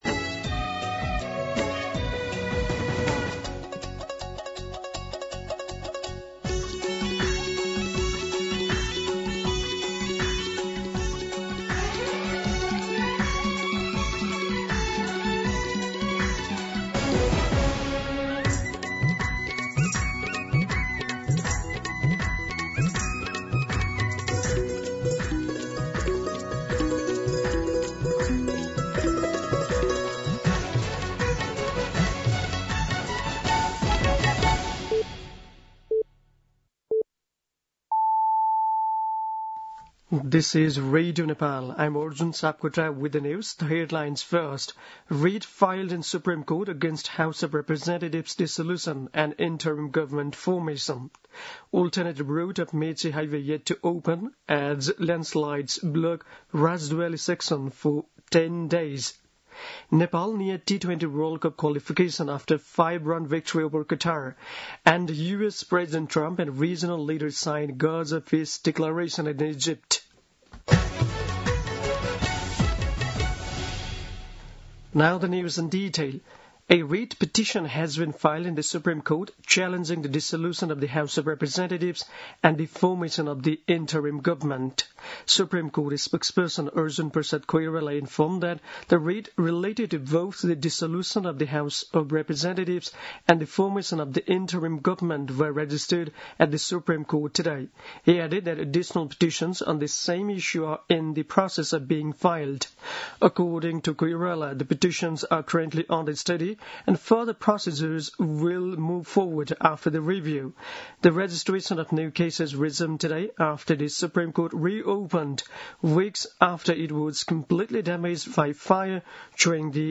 दिउँसो २ बजेको अङ्ग्रेजी समाचार : २८ असोज , २०८२